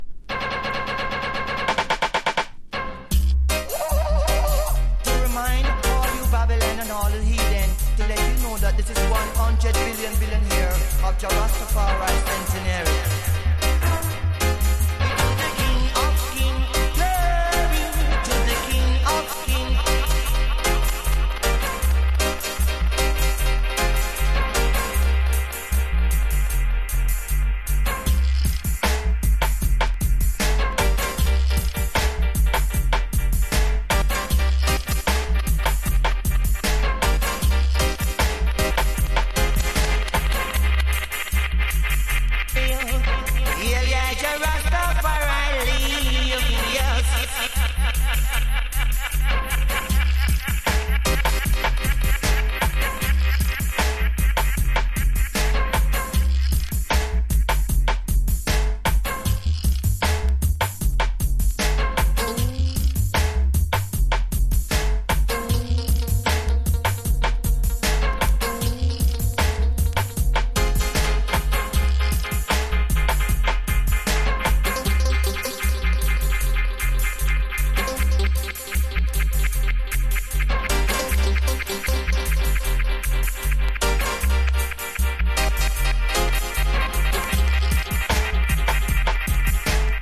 1. REGGAE >
# ROOTS# DUB / UK DUB / NEW ROOTS# REGGAE